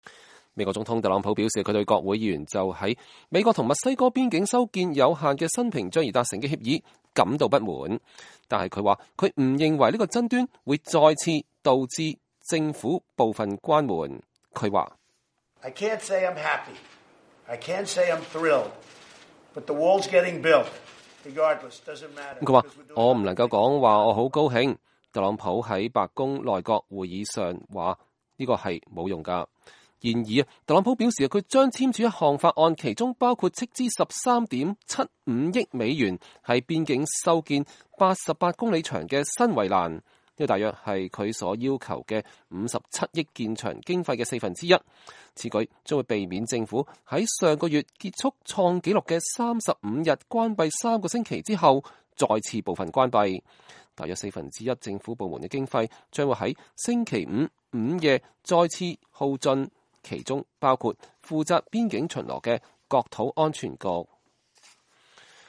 2019年2月12日特朗普總統在白宮內閣會議上講話。